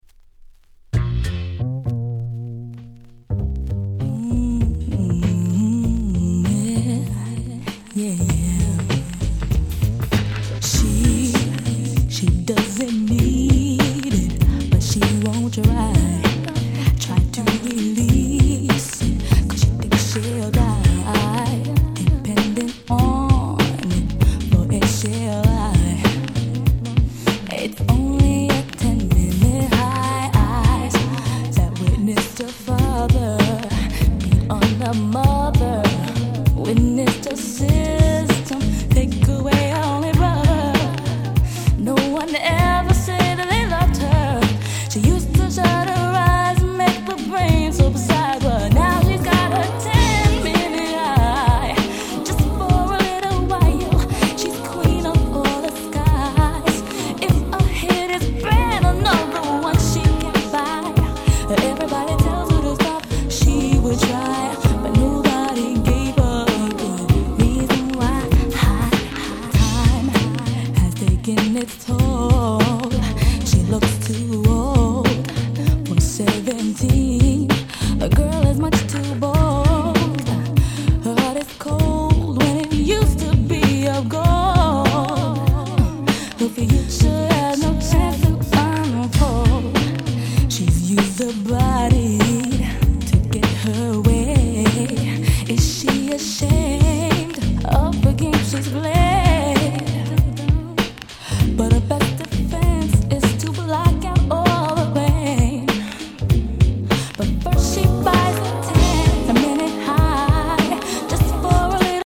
95' Nice R&B !!
派手さは無い物の、彼女達のカラーが色濃く出たNeo Soulチューン。